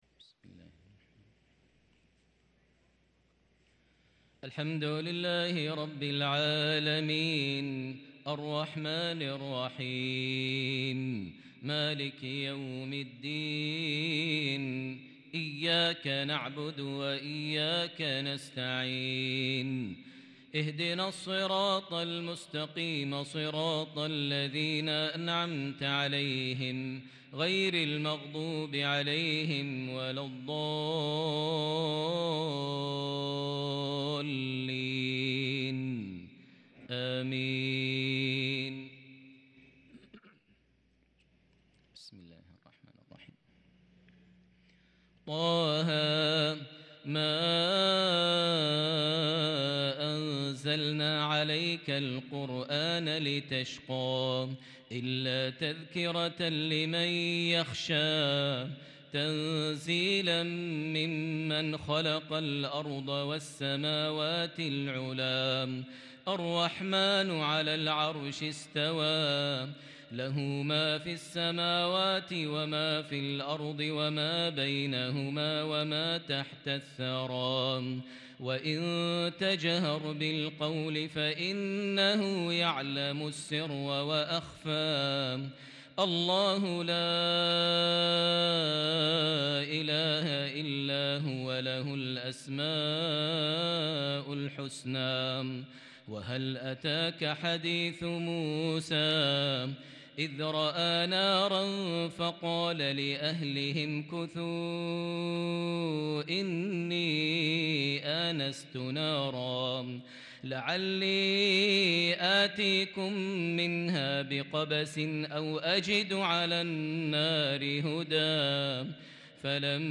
صلاة العشاء للقارئ ماهر المعيقلي 23 ذو الحجة 1443 هـ